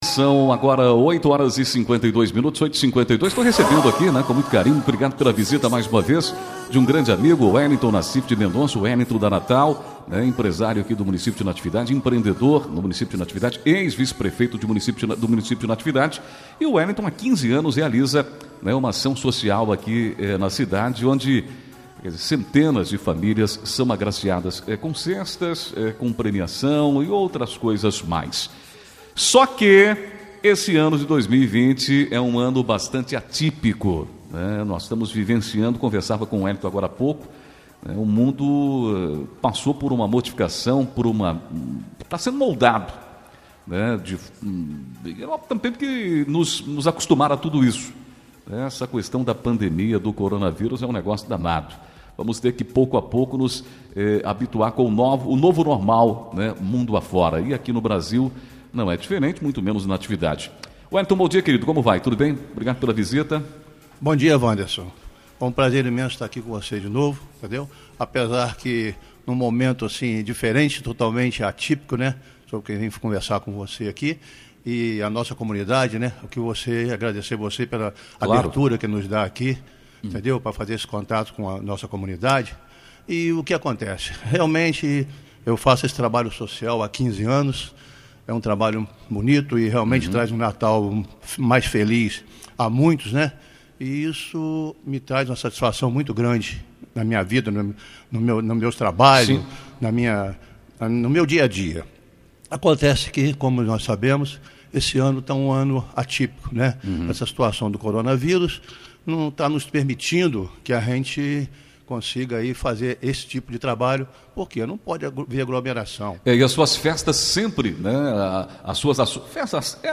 18 dezembro, 2020 DESTAQUE, ENTREVISTAS